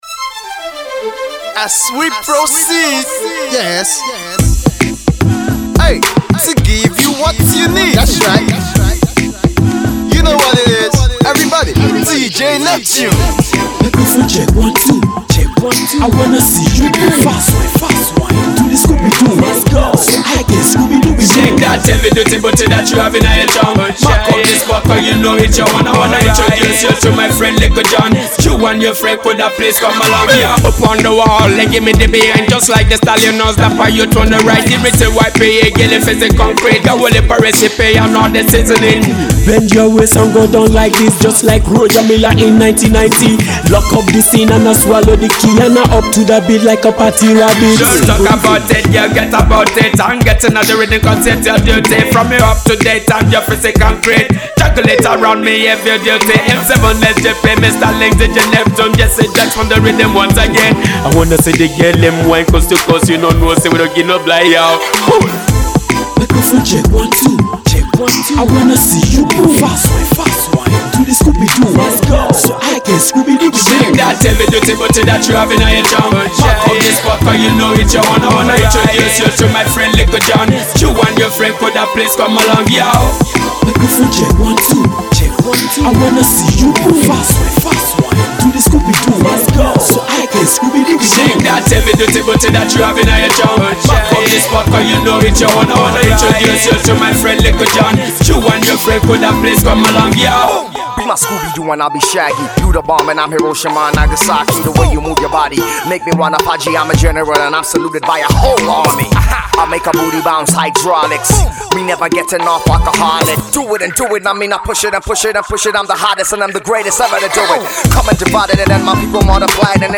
a bumping jam for the clubs